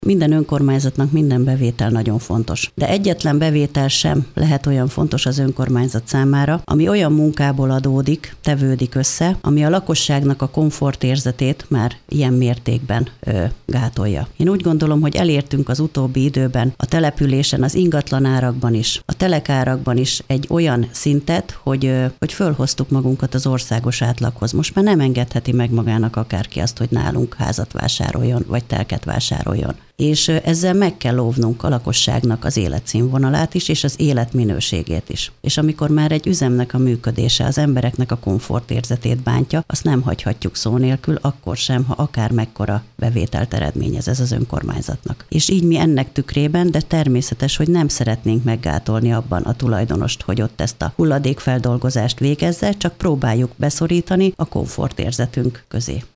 Nagy Andrásné polgármestert hallják.